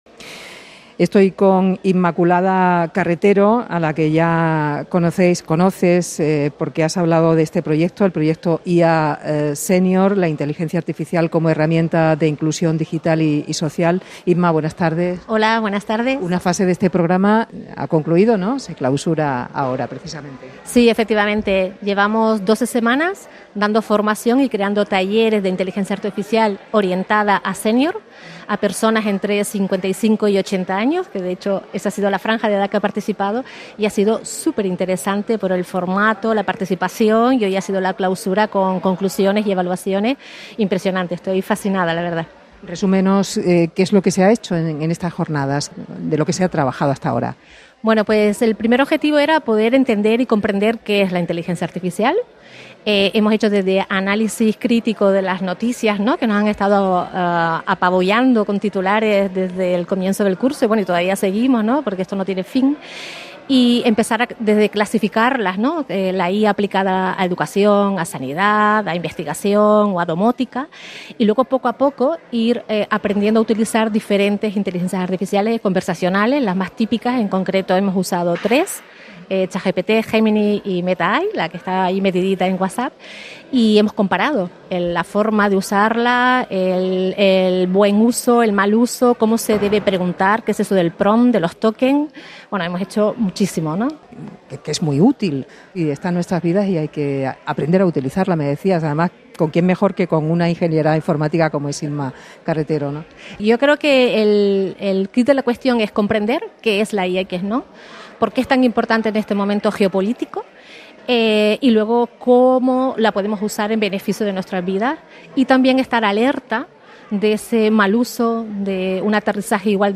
Canal Extremadura Radio nos acompaña en la clausura de la primera fase del Proyecto IA Sénior.
Escucha la entrevista de Canal Extremadura Radio